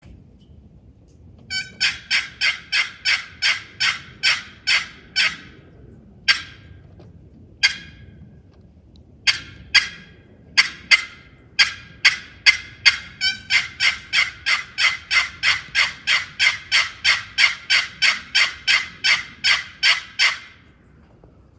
Bloodwood over Walnut - High Class Calls